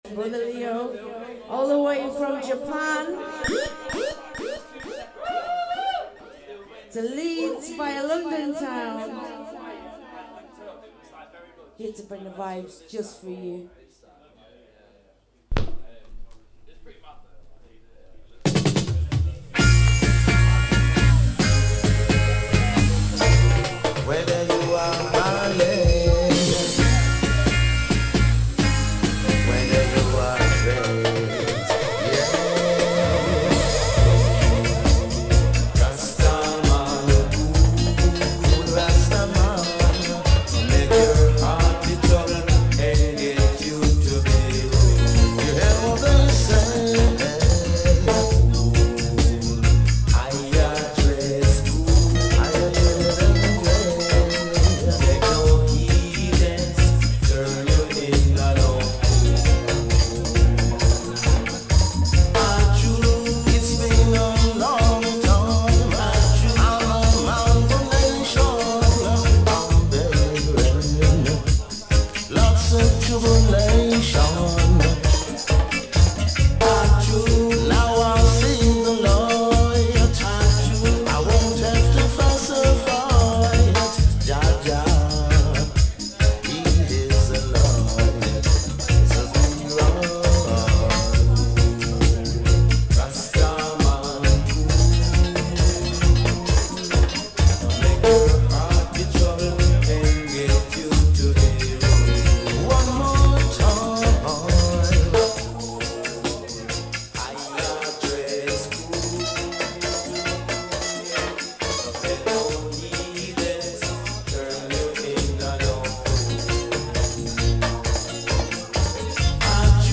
Leeds UK June 2025